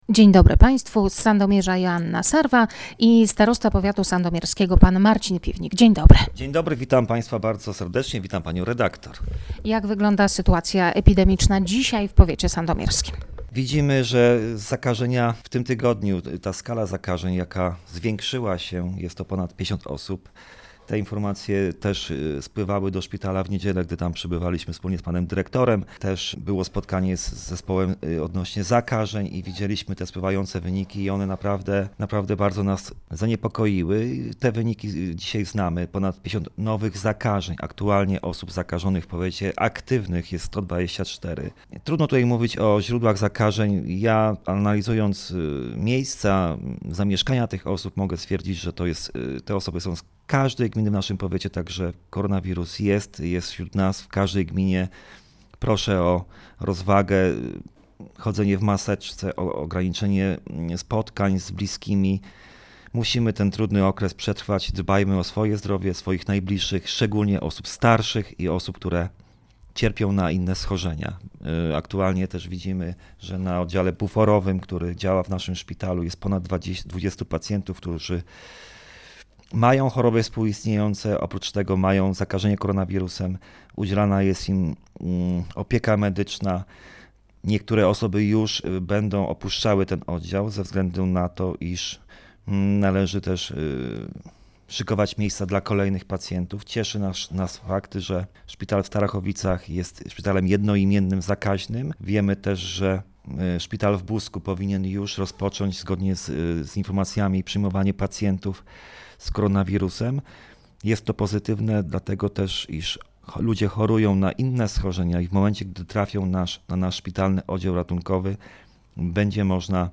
Rozmowa ze starostą Marcinem Piwnikiem.
O aktualnej sytuacji epidemicznej w powiecie sandomierskim w rozmowie z Radiem Leliwa starosta sandomierski Marcin Piwnik: